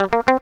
GTR 94 CM.wav